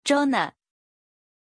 Pronunciation of Jonah
pronunciation-jonah-zh.mp3